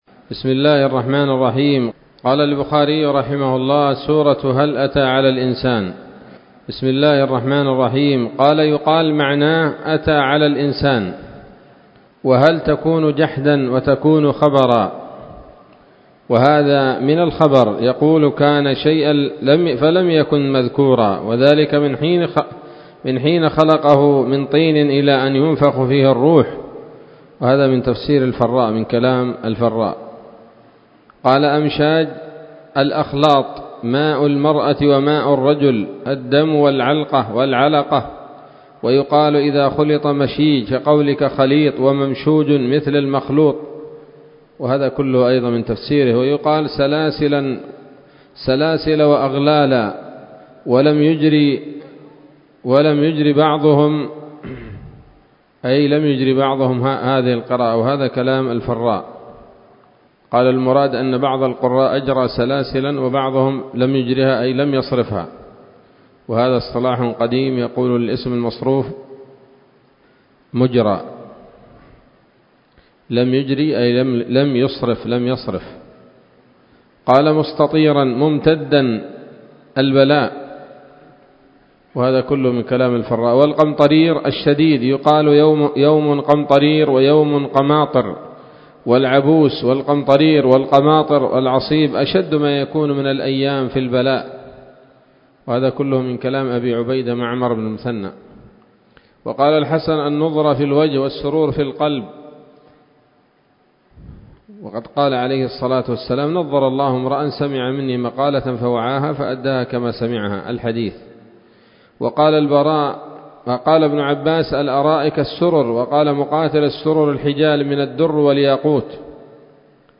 الدرس الثامن والسبعون بعد المائتين من كتاب التفسير من صحيح الإمام البخاري